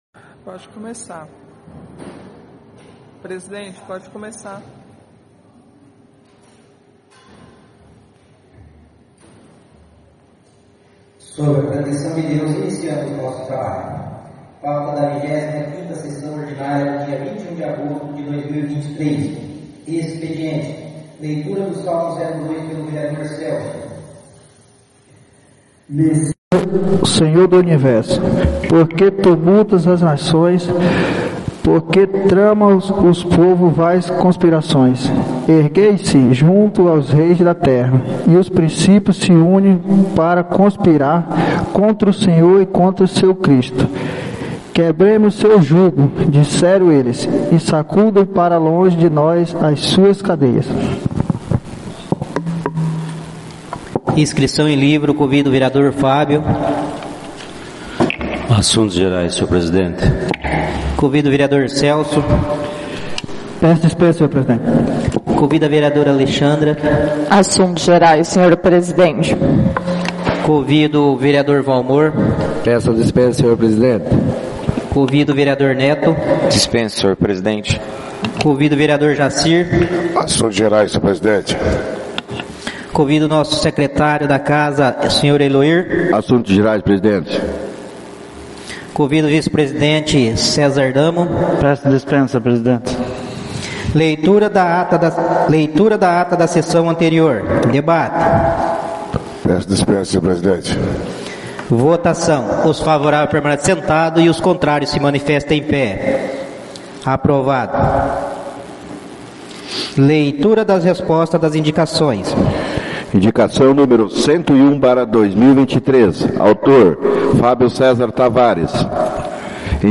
25ª SESSÃO ORDINÁRIA - 21/08/2023